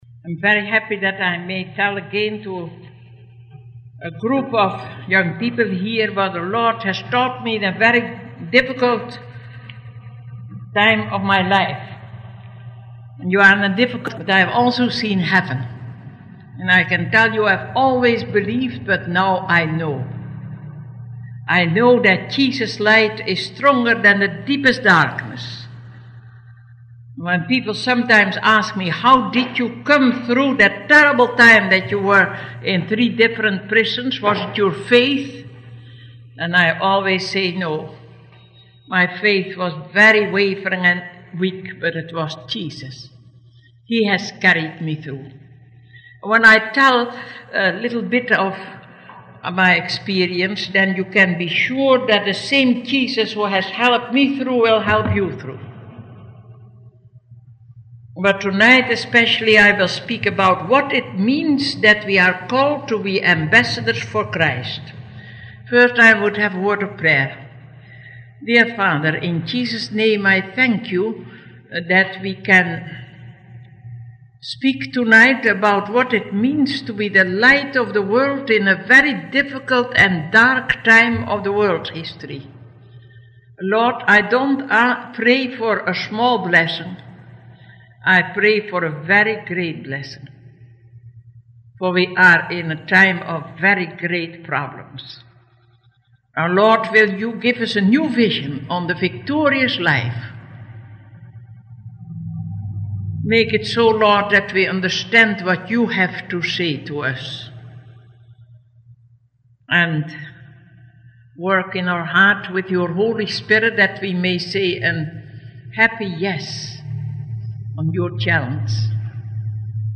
A sermon from the Reel-to-Reel collection in the Holy Spirit Research Center, Oral Roberts University.